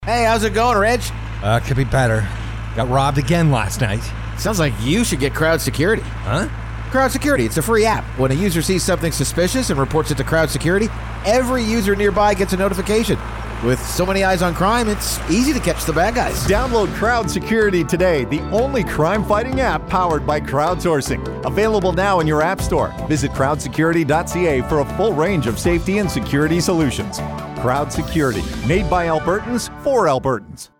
Crowd Security Radio Ad as Heard on Major Alberta Radio Stations – Crowd Security Inc